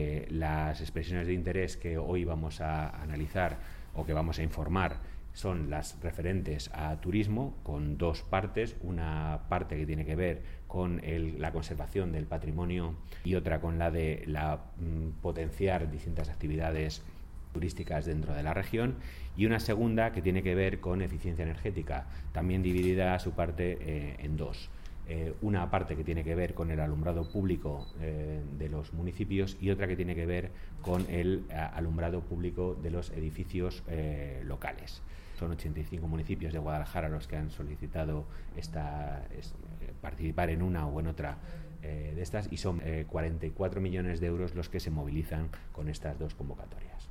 El director general de Coordinación y Planificación, Eusebio Robles, habla de los municipios de la provincia de Guadalajara que han presentado proyectos a las convocatorias de turismo y eficienca energética.